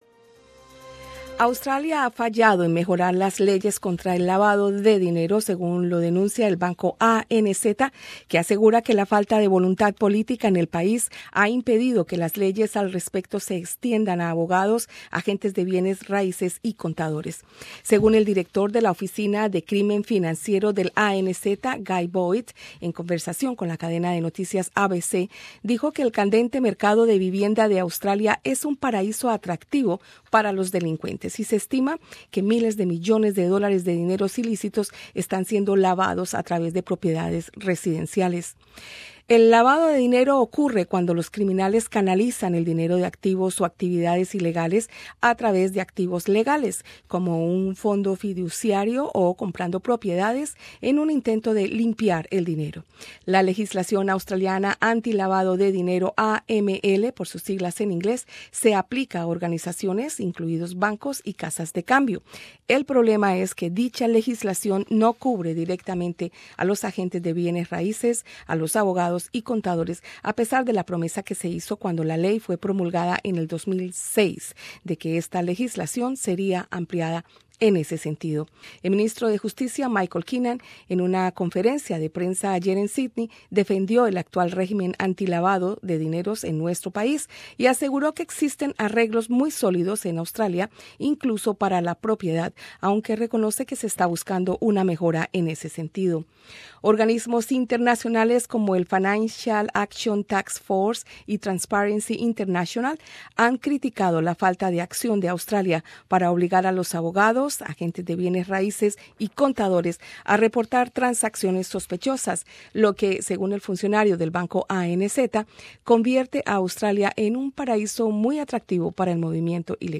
Australia ha fallado en mejorar las leyes contra el lavado de dinero, según lo denuncia el Banco ANZ, que asegura que la falta de voluntad política en el país ha impedido que las leyes al respecto se extiendan a abogados, agentes de bienes raíces y contadores. Entrevista